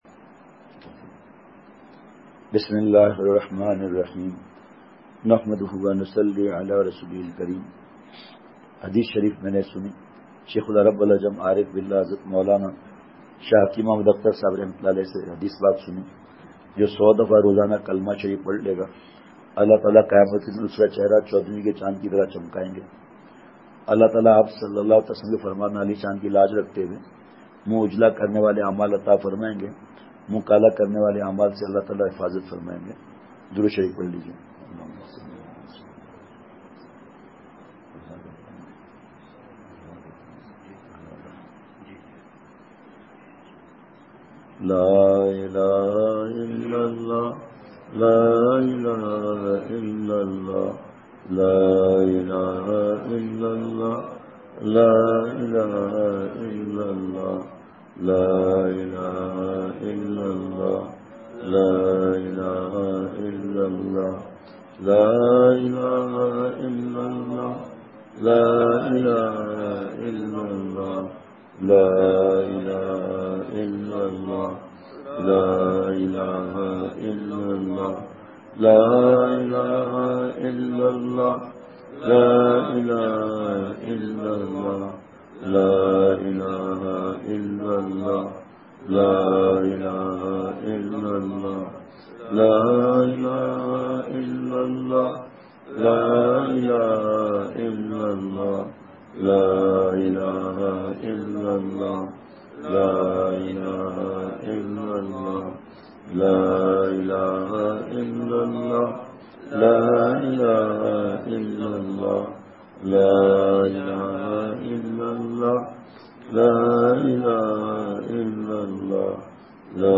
بعد ازفجر بیان